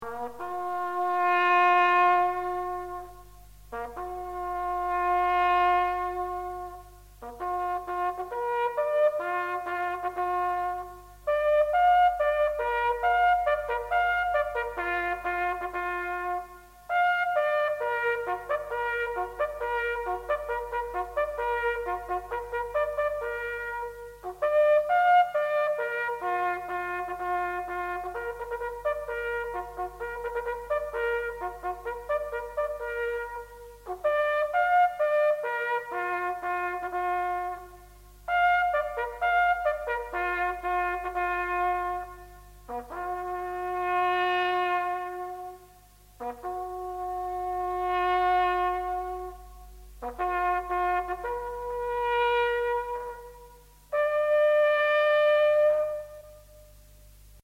Last Post
Last-Post.mp3